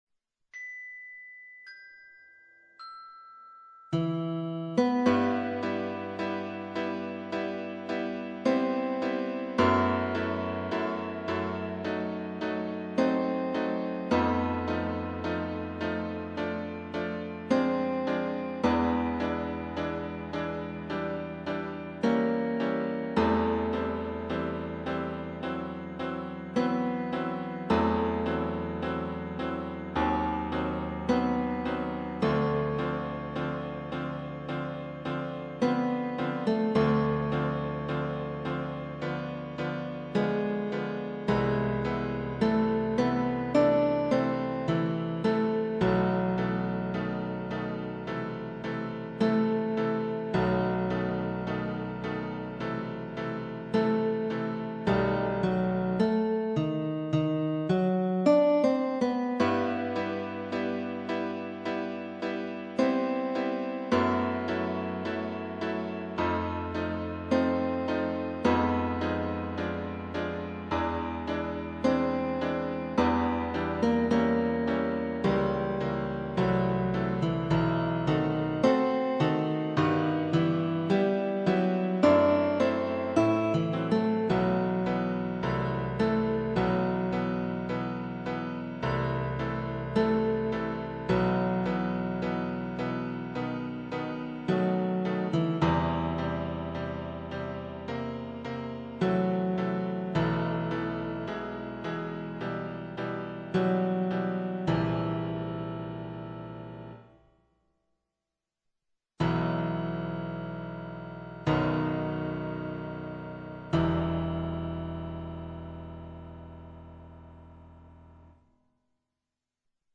Il Preludio in mi minore n. 4 op. 28 di F. Chopin presenta una melodia di notevole espressività e bellezza.
Lo proponiamo in versione didattica per flauto.